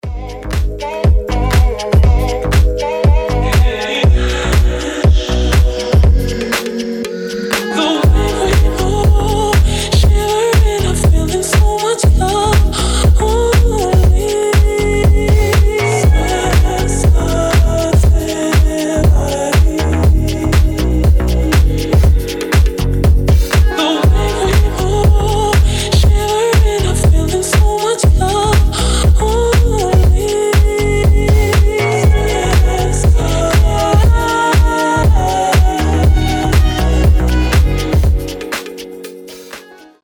• Качество: 320, Stereo
deep house
EDM
чувственные
nu disco
Стиль: nu disco, deep house